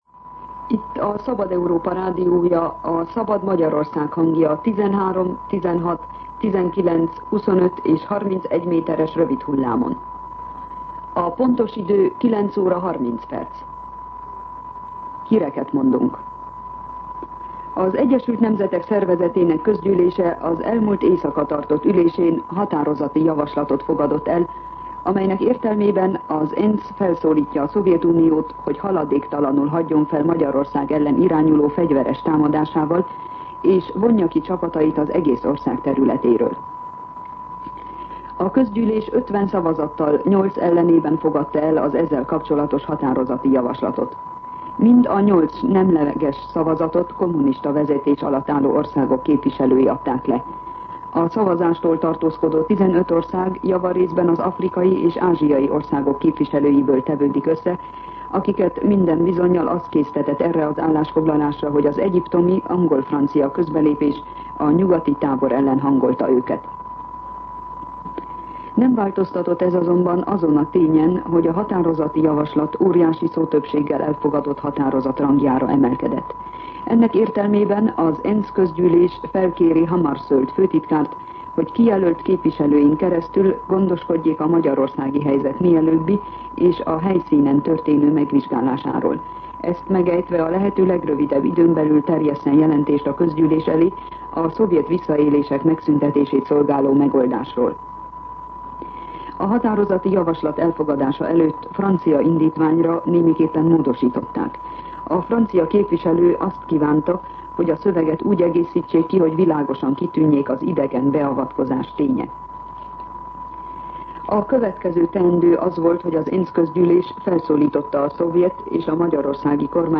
09:30 óra. Hírszolgálat